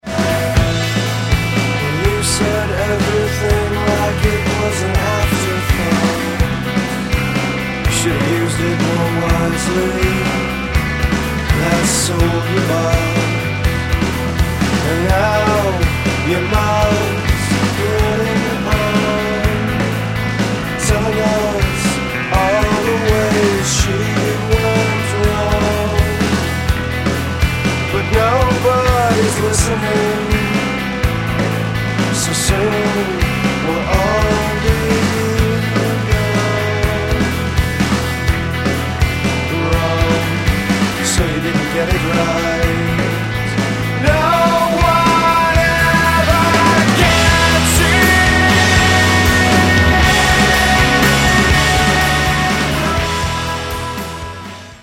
my favorite mid-tempo song